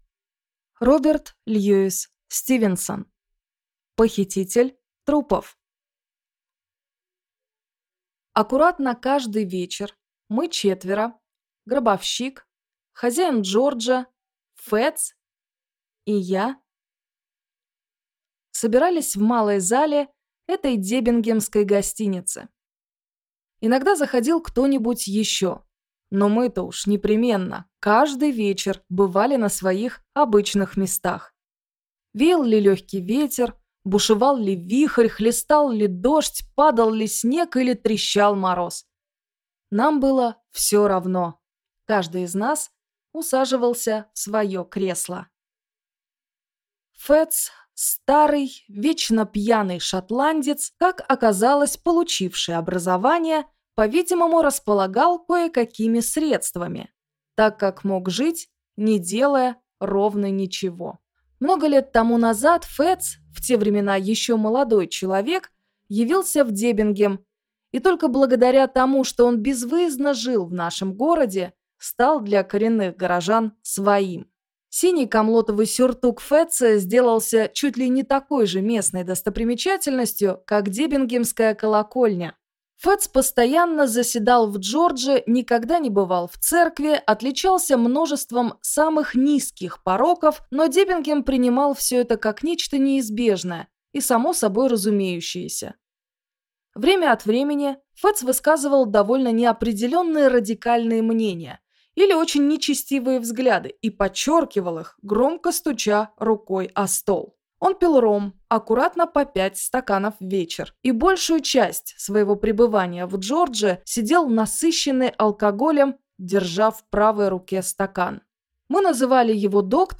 Аудиокнига Похититель трупов | Библиотека аудиокниг